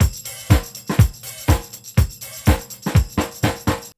INC BEAT2 -R.wav